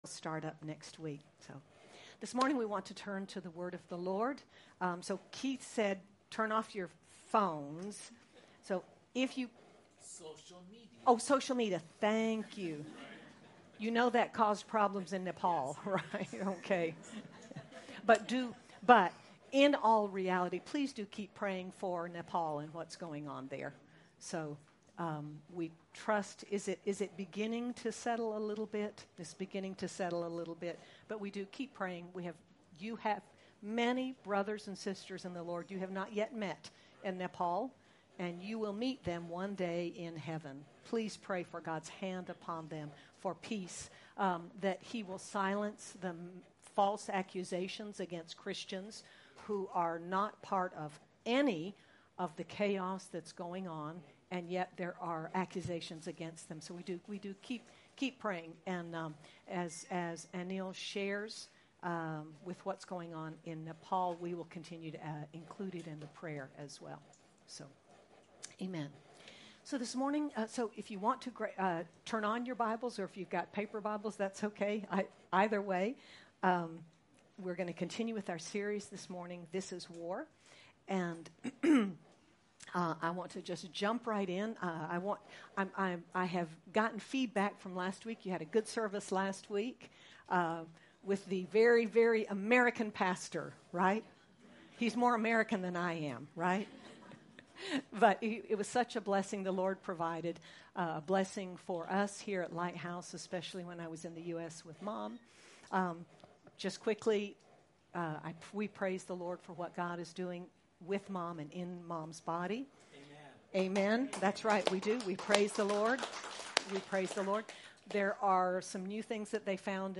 Sep 16, 2025 Mighty Spiritual Weapons—Praise MP3 SUBSCRIBE on iTunes(Podcast) Notes Discussion Sermons in this Series Because we are in a spiritual war, we must use spiritual weapons. One of the mighty spiritual weapons we have is praise. Let’s see how Joshua, Jehoshaphat, and Paul and Silas won the victory through praise. Sermon by